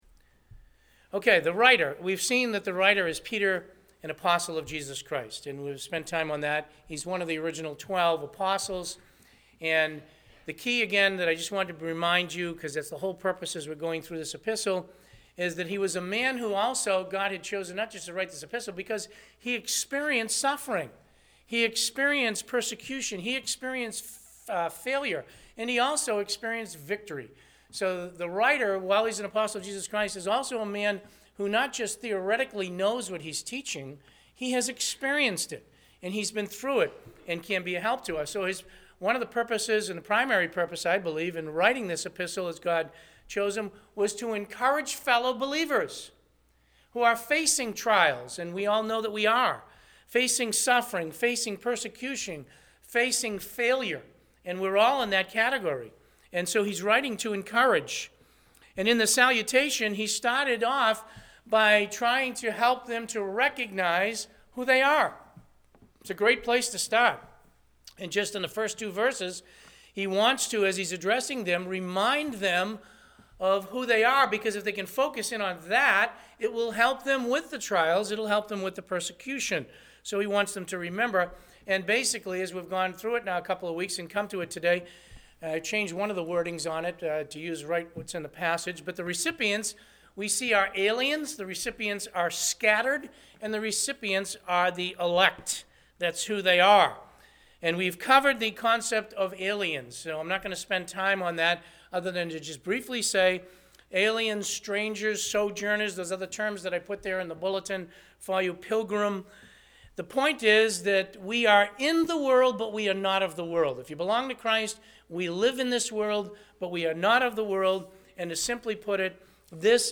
Listen to the sermon “The Recipients – Part 2.”